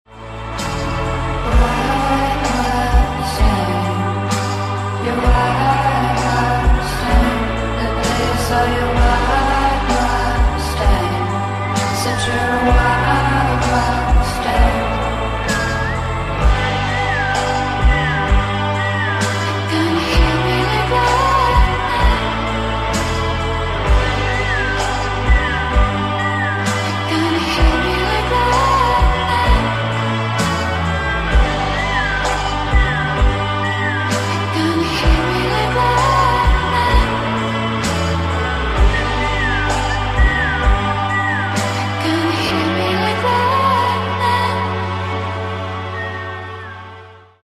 (Mashup)